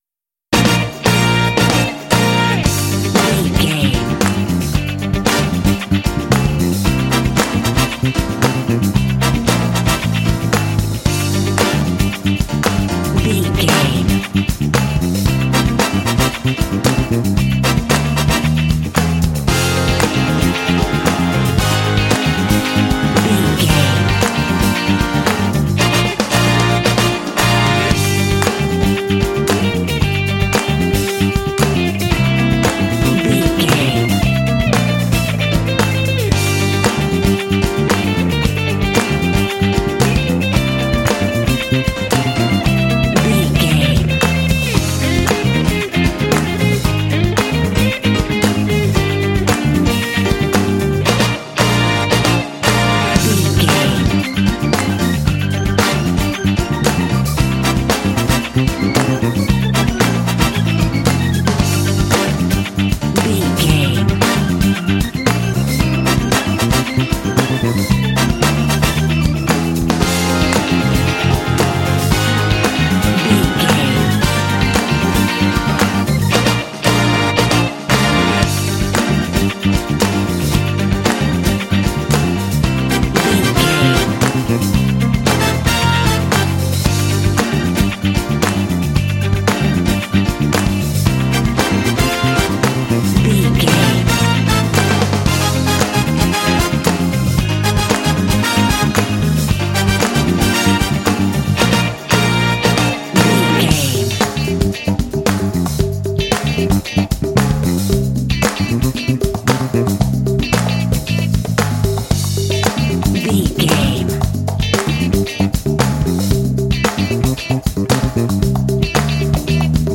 Aeolian/Minor
funky
groovy
bright
lively
energetic
bass guitar
electric guitar
drums
brass
piano
electric organ
strings
Funk
jazz